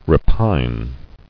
[re·pine]